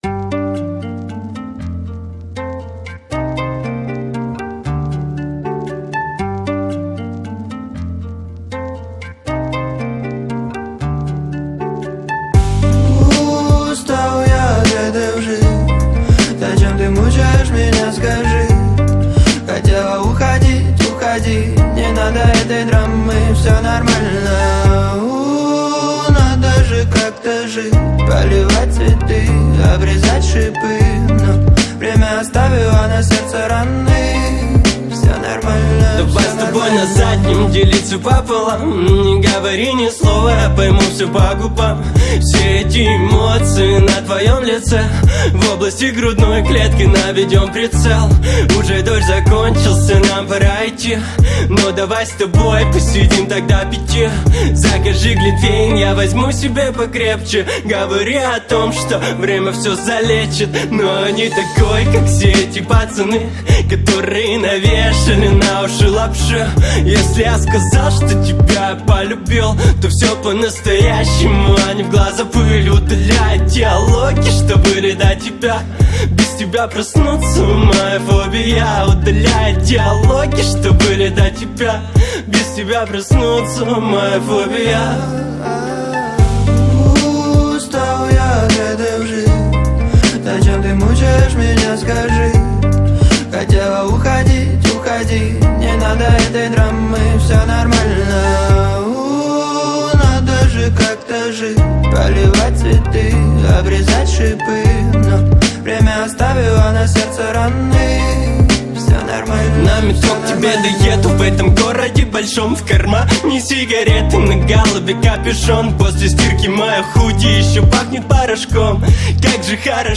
это трек в жанре хип-хоп